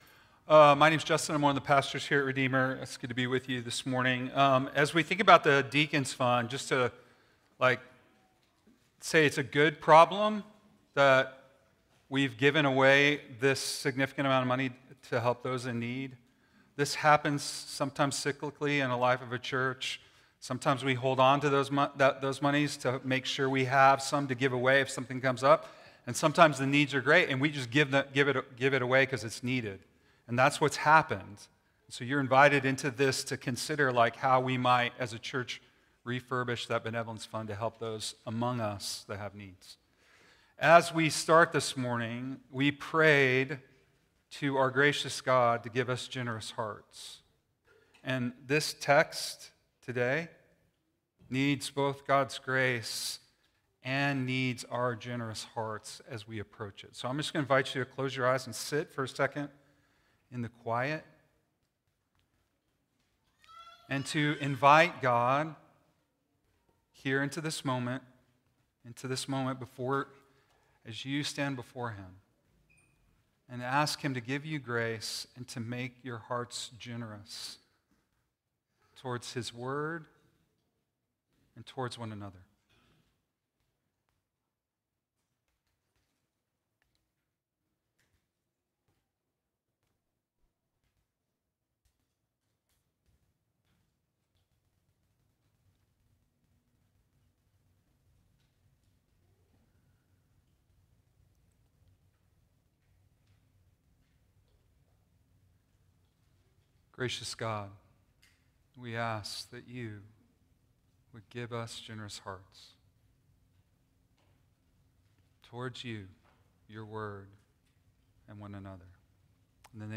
10_19 sermon - Made with Clipchamp.m4a